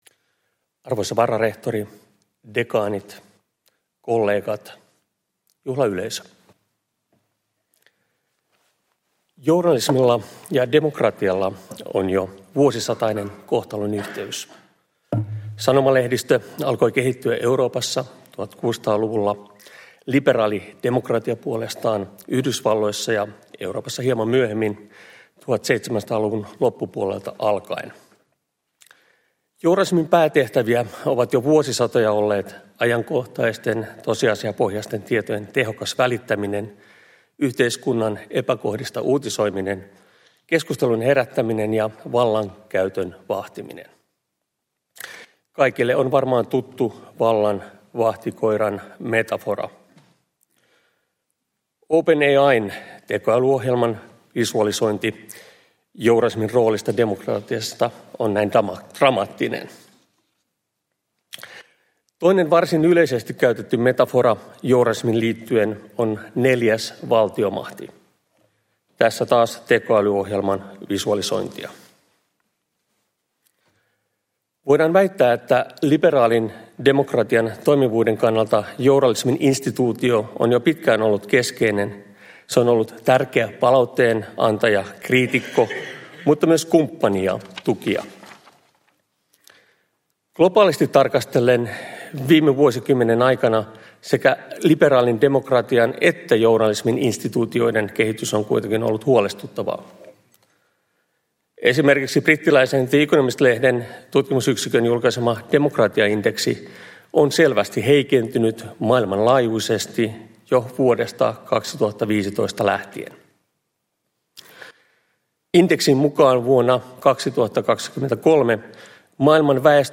Uusien professoreiden juhlaluennot 2024